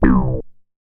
MoogVoco 003.WAV